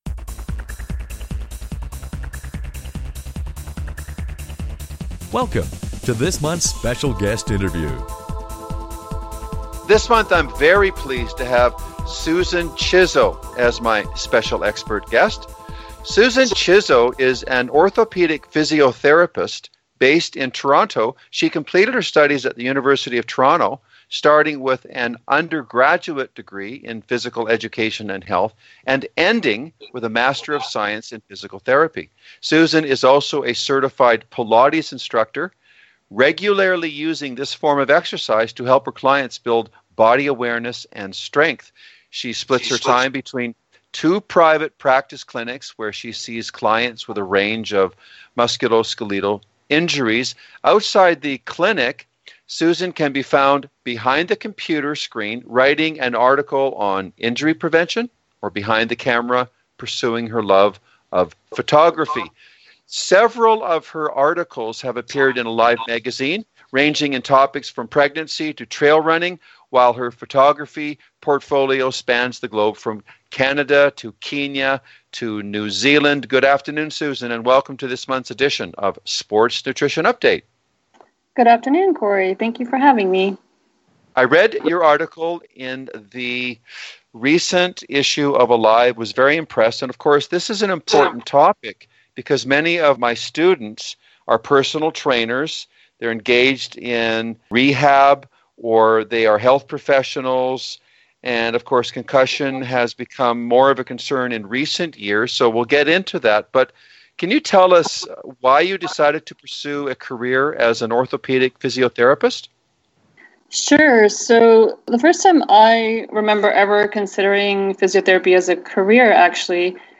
Special Guest Interview Volume 17 Number 8 V17N8c